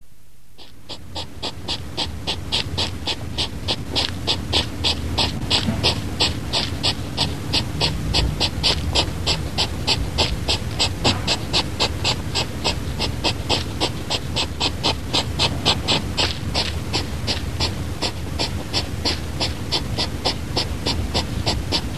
На этой странице собраны натуральные звуки ежей: фырканье, шуршание листьев и другие характерные шумы.
Фырканье ежа